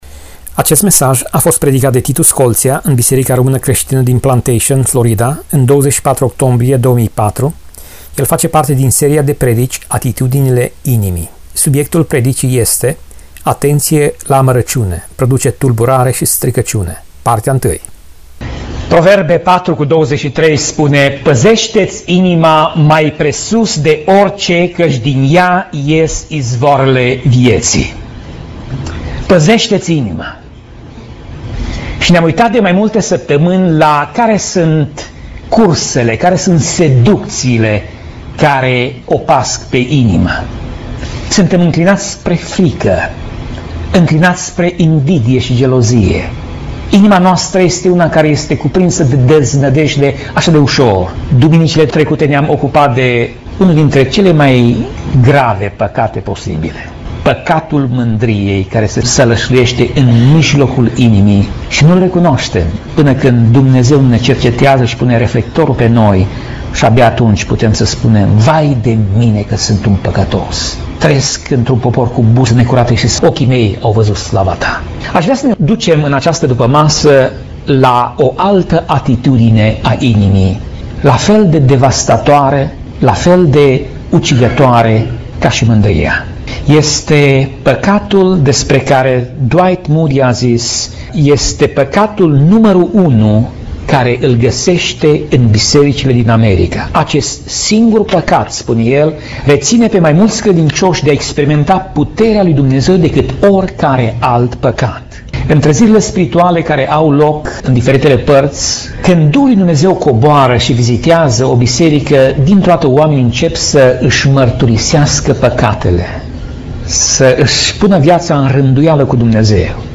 Tip Mesaj: Predica Serie: Atitudinile inimii